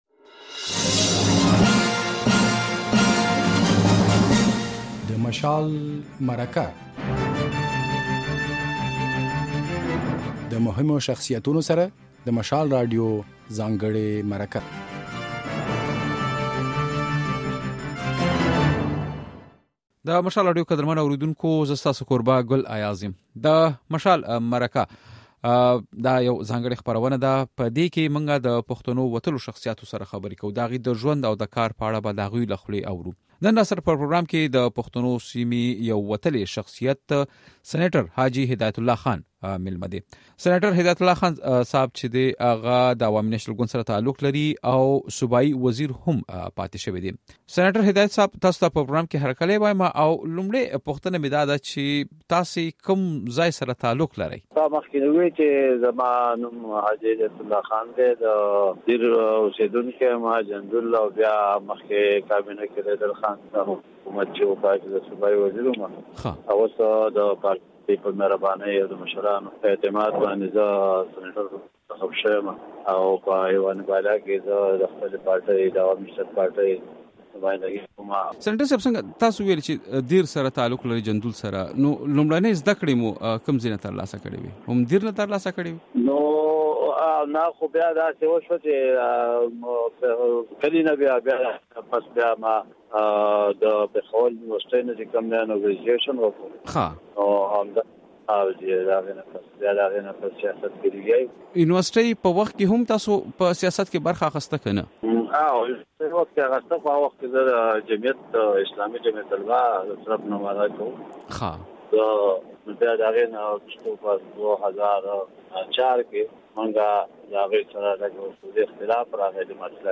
د مشال مرکه کې مو سېنېټر هدايت الله خان مېلمه دی.